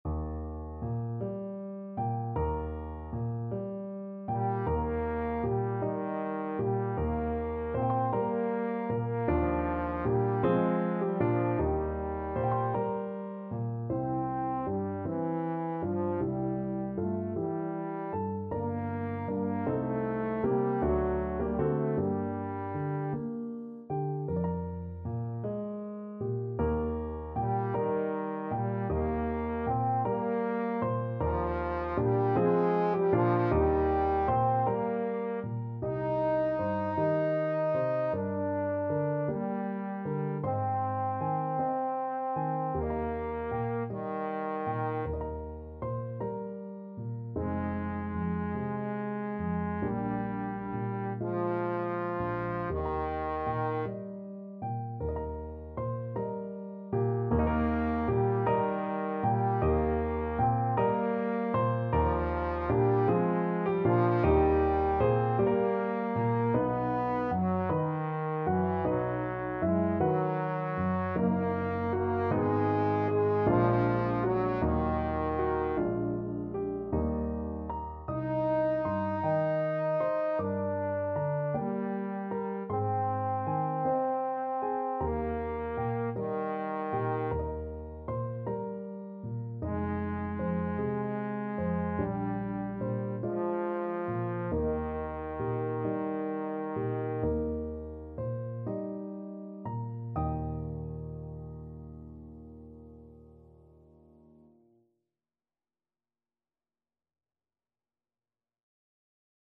6/8 (View more 6/8 Music)
~. = 52 Allegretto
Eb4-Eb5
Classical (View more Classical Trombone Music)